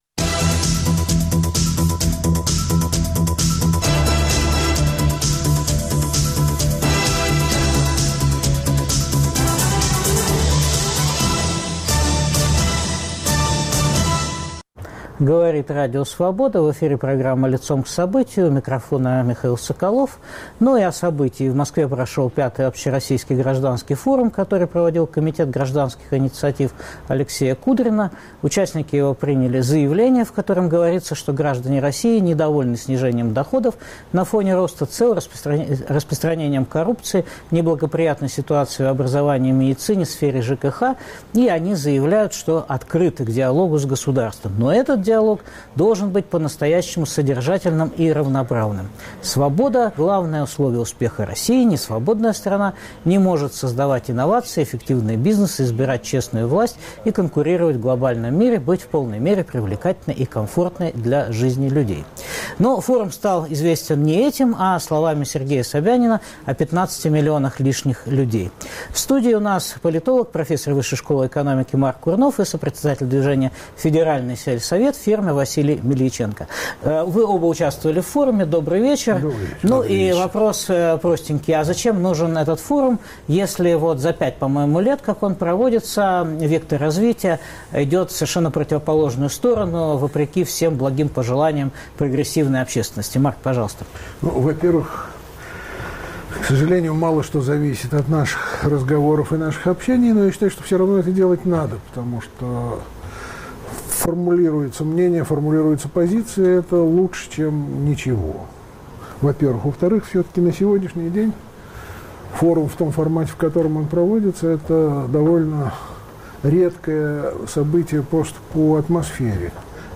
обсуждают профессор, доктор политических наук